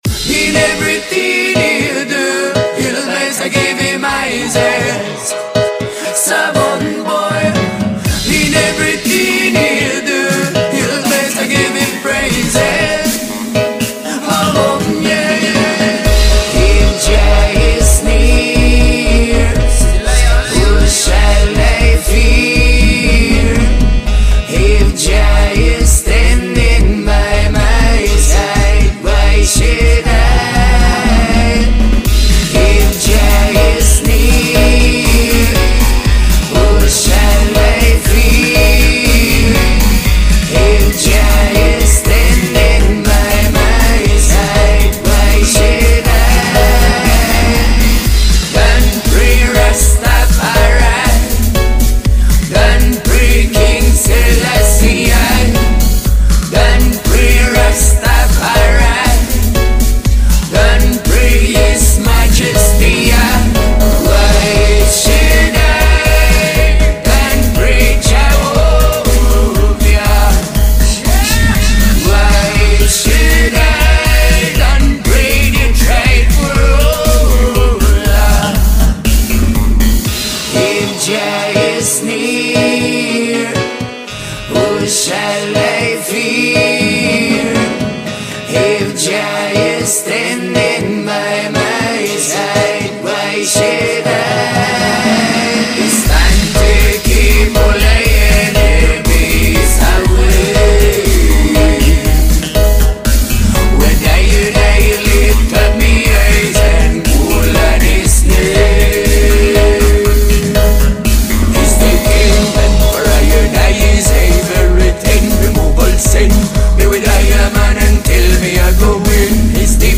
Strictly Italians vibes inna dubwise style